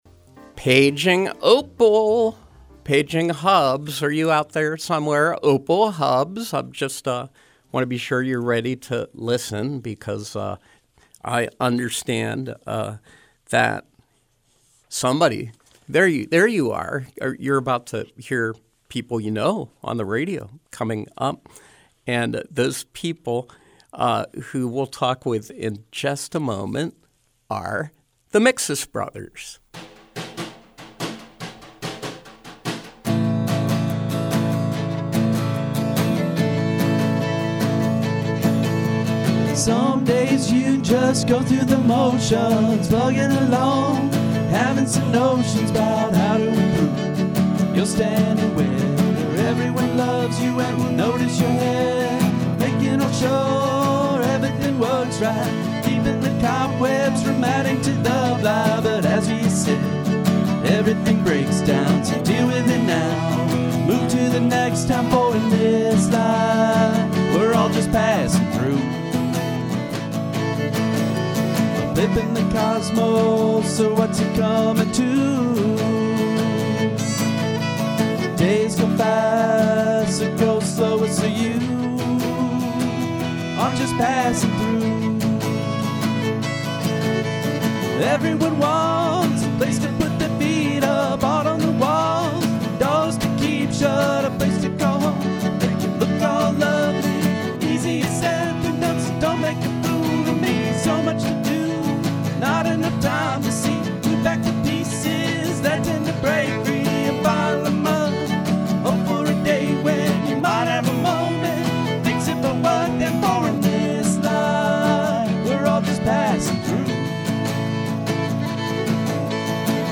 Music and conversation with indie folk band
guitar, vocals
drums, vocals
fiddle